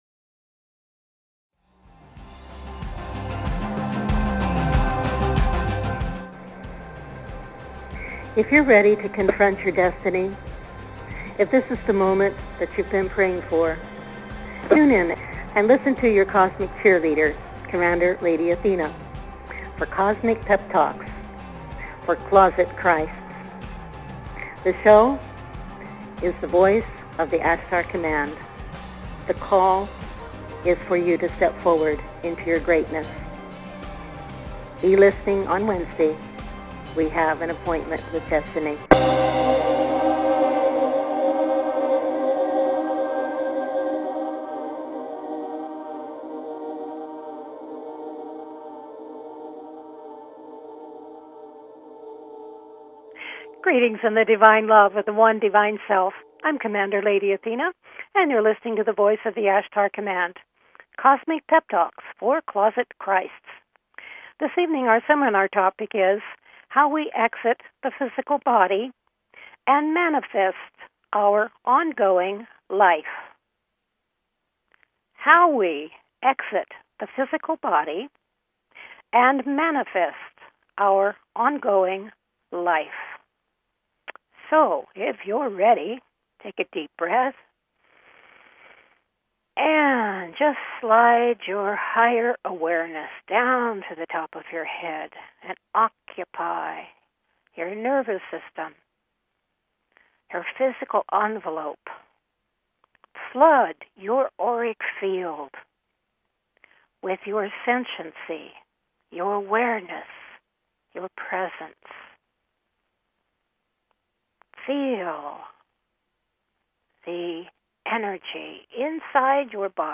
Our BBS radio shows are quantum awareness Intensives especially designed to provide deeper insight into your life as an immortal Divine Whole Light Being, your Soul purpose and how to integrate that into your daily life. Various experiential processes, meditations and teachings evoke your Divine knowing and Identity, drawing you into deeper com